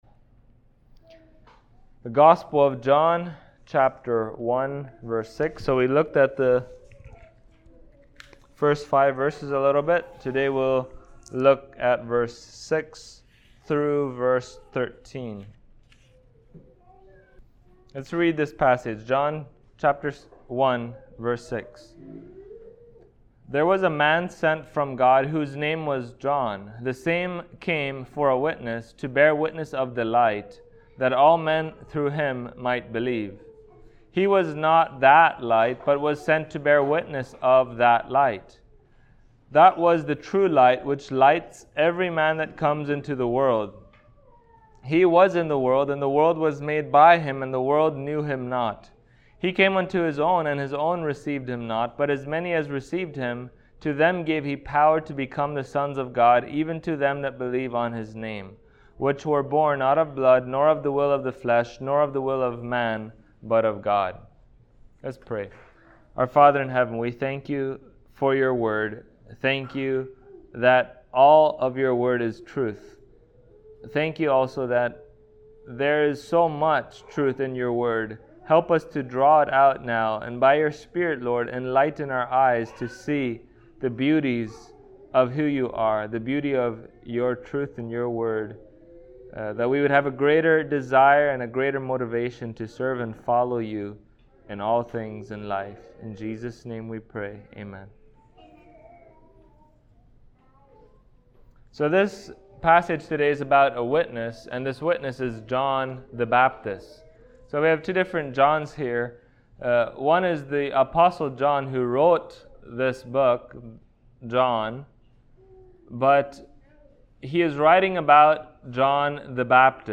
John Passage: John 1:6-13 Service Type: Sunday Morning Topics